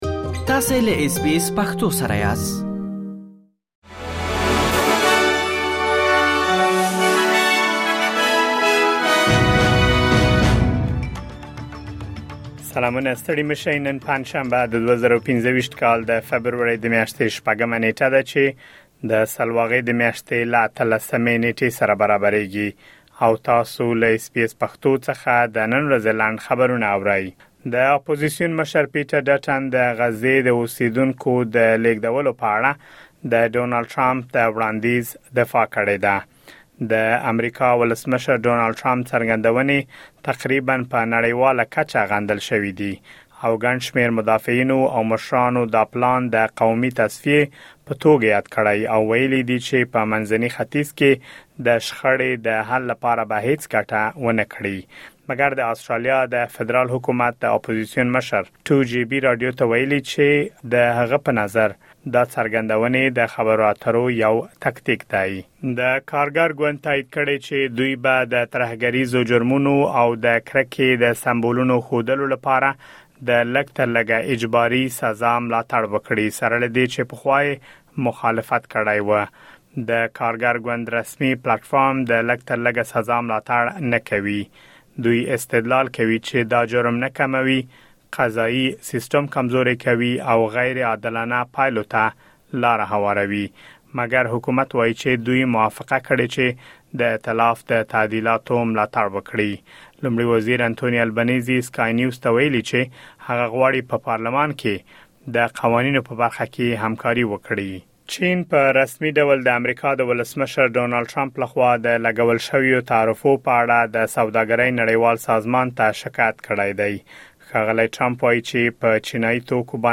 د اس بي اس پښتو د نن ورځې لنډ خبرونه | ۶ فبروري ۲۰۲۵